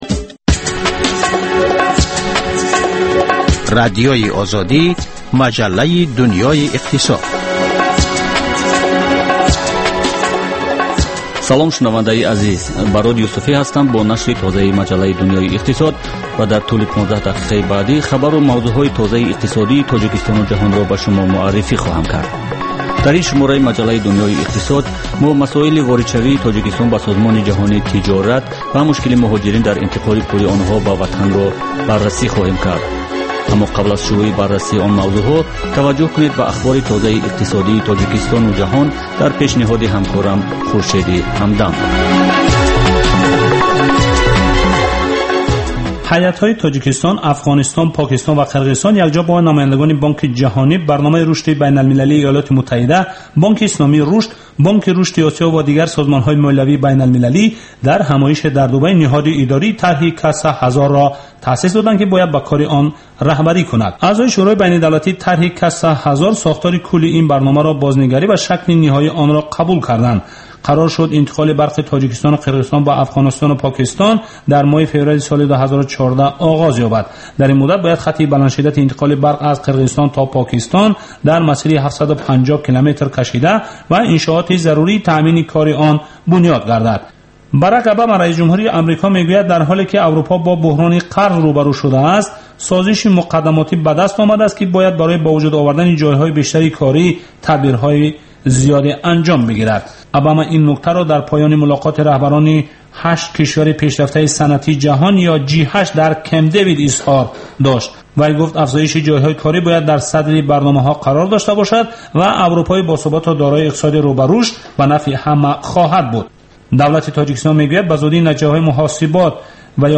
Хабарҳои иқтисодии Тоҷикистон, минтақа ва ҷаҳон. Баррасии фарояндаҳо ва падидаҳои муҳим дар гуфтугӯ бо коршиносон.